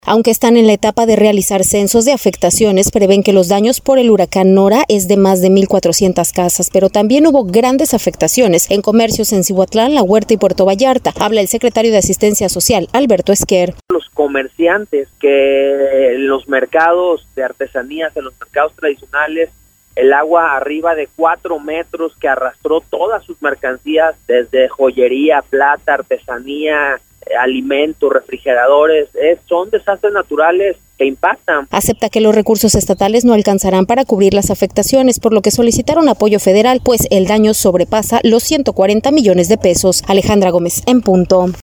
Habla el secretario de Asistencia Social, Alberto Esquer: